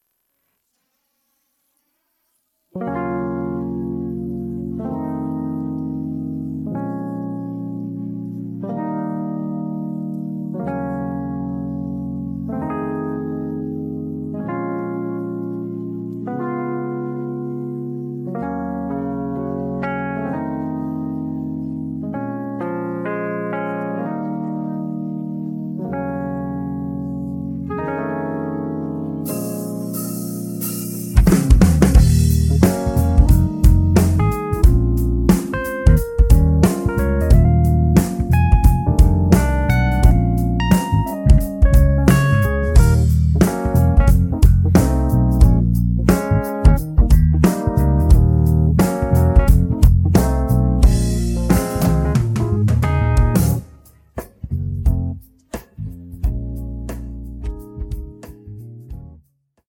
음정 -1키 4:22
장르 가요 구분 Voice Cut